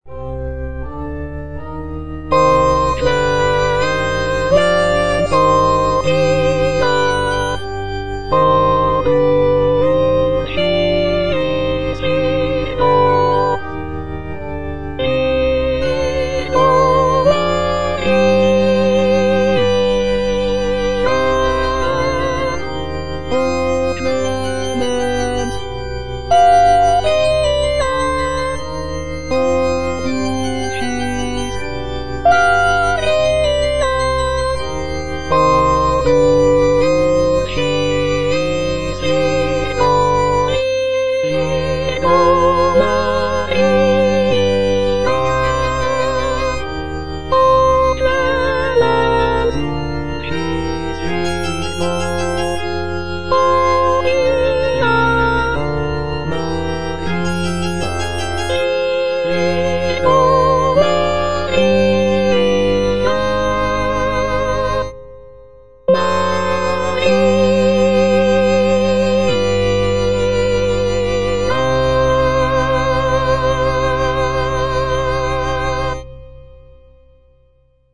G.B. PERGOLESI - SALVE REGINA IN C MINOR O clemens, o pia - Soprano (Emphasised voice and other voices) Ads stop: auto-stop